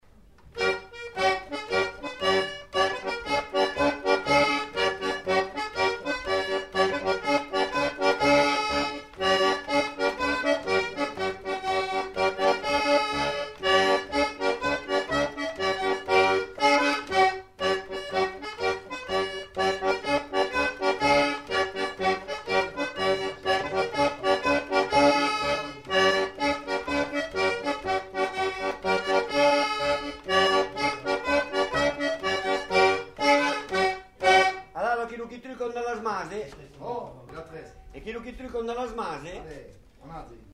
Lieu : Pyrénées-Atlantiques
Genre : morceau instrumental
Instrument de musique : accordéon diatonique
Danse : quadrille (2e f.)